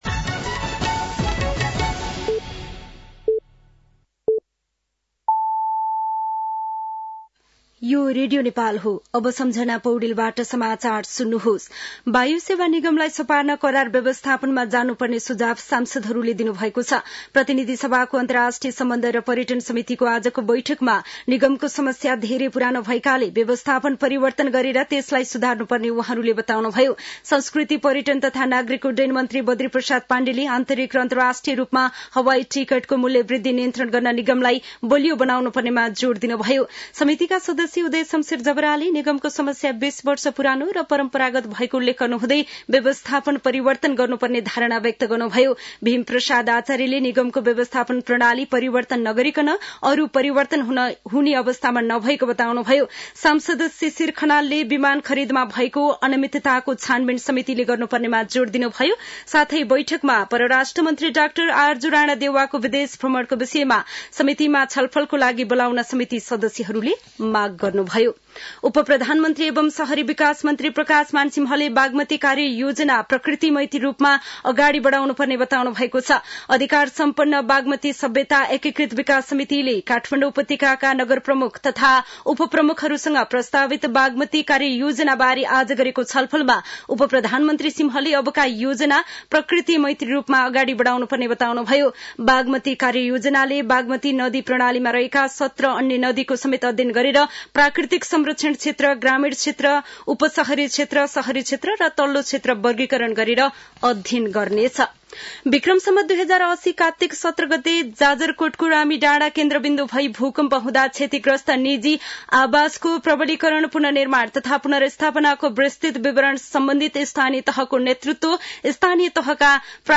साँझ ५ बजेको नेपाली समाचार : २४ पुष , २०८१
5-pm-nepali-news-9-23.mp3